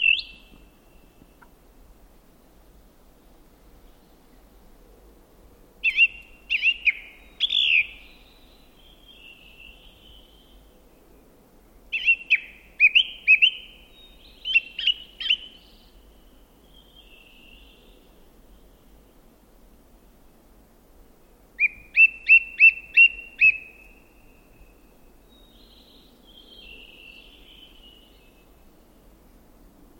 grive-musicienne.mp3